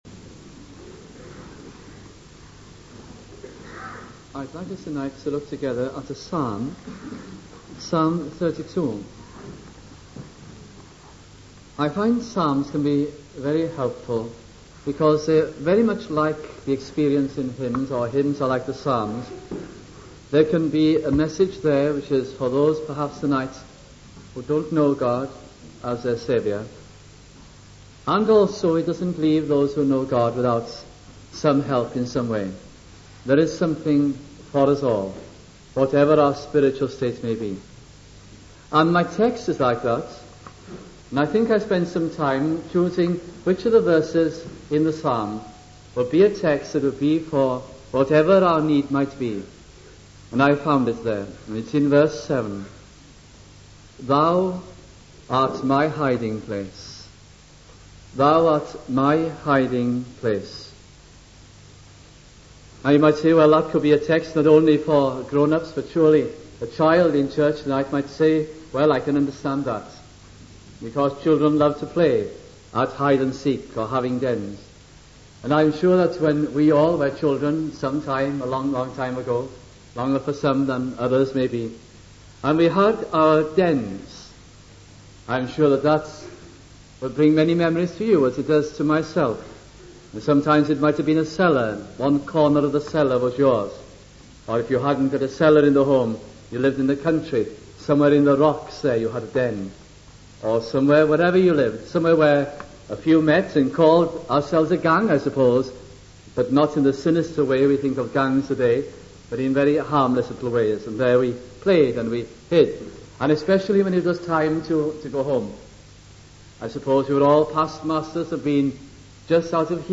» Psalms Gospel Sermons